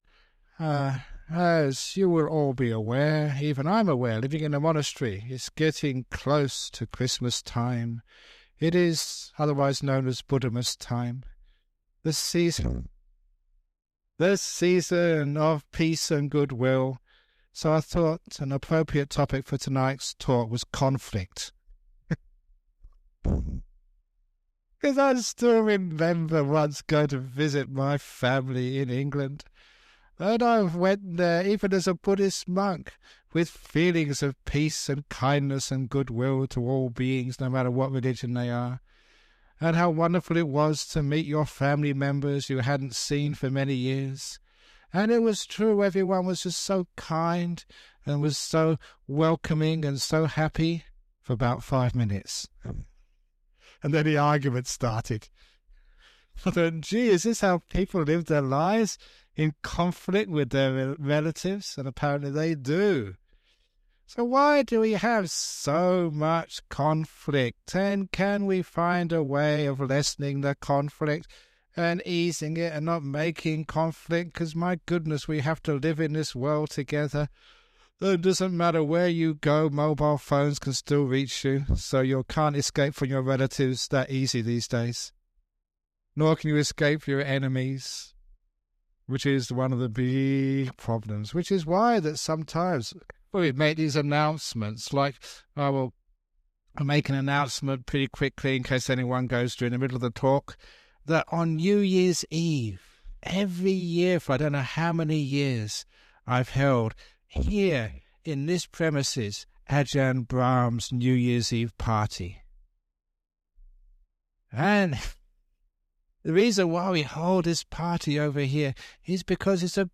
In this talk, Ajahn Brahm discusses the topic of conflict and suggests that people should have compassion and understanding towards their enemies. He emphasizes that people should focus on criticizing the action rather than the person, as attacking the person will only lead to more conflict.